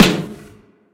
GBOM_SNR.wav